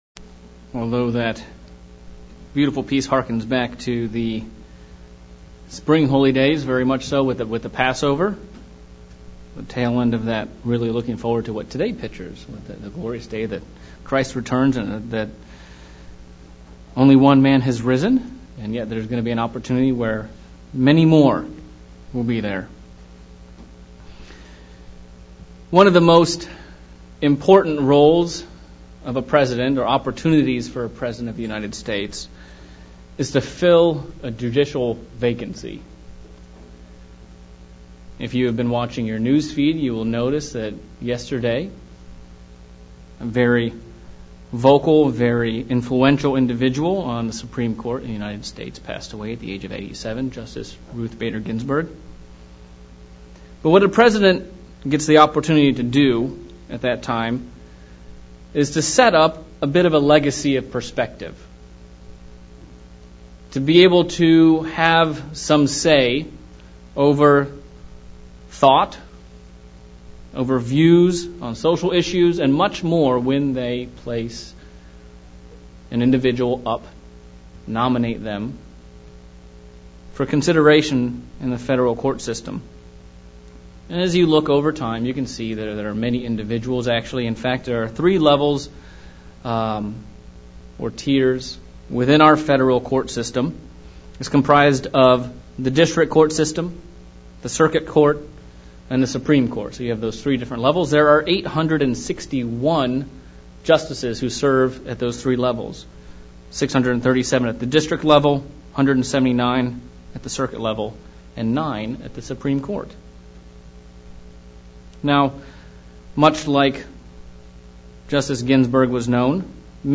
Trumpets PM sermon looking at the qualifications necessary to be appointed as a jjudge and what it woiuld take for us to so appointed.